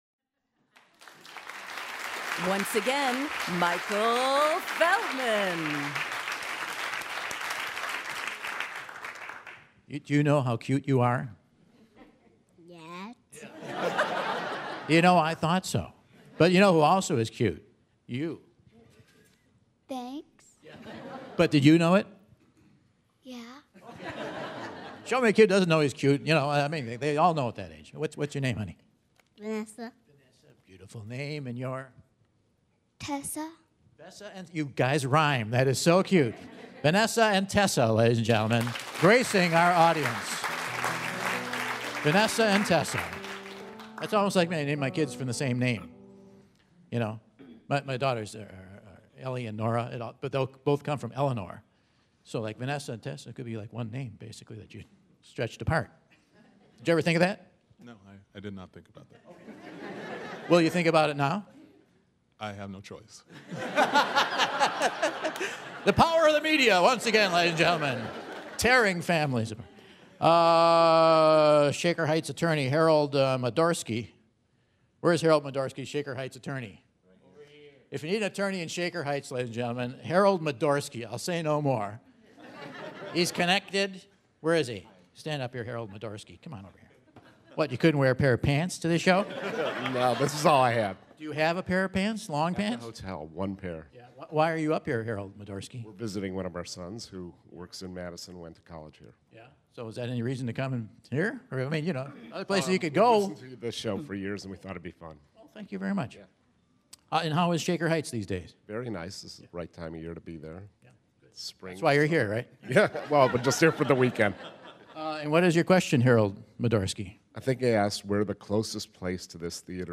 Two contestants feel the weight of the Whad'Ya Know? Quiz!